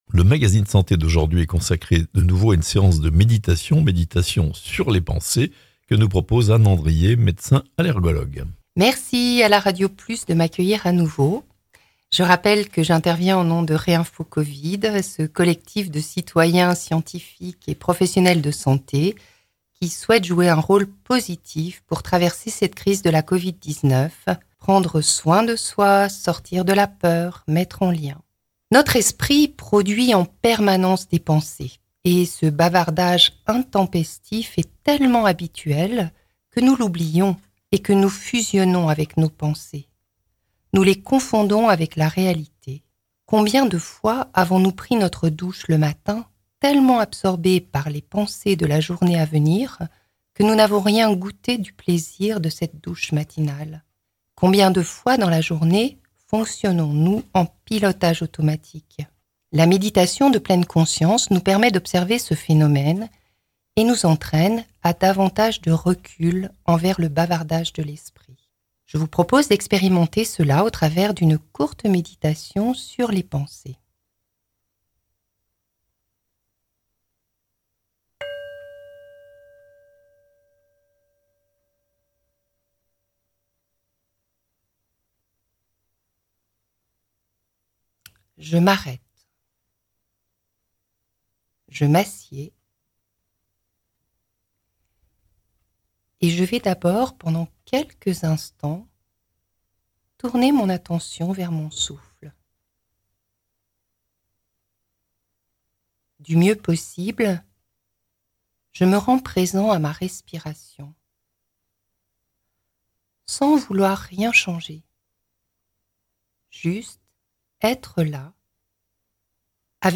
le-magazine-de-la-sante seance-de-meditation reinfo-covid-74 reinfosante-fr